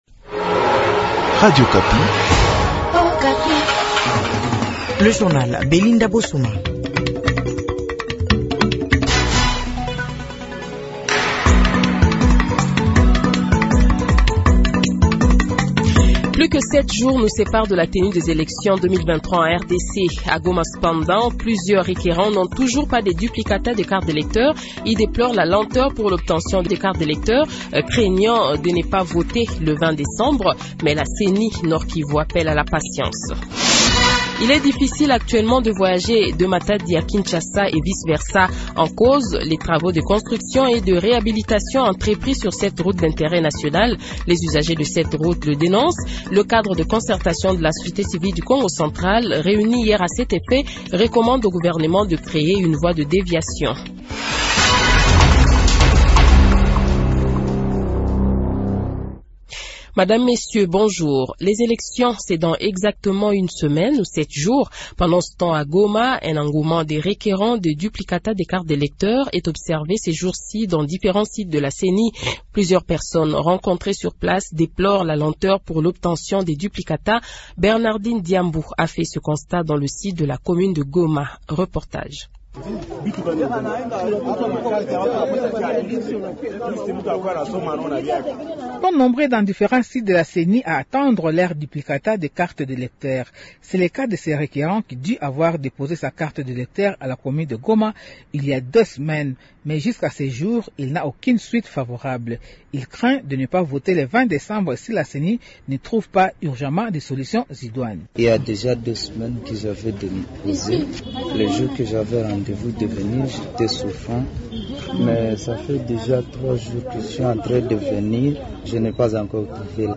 Journal Francais Matin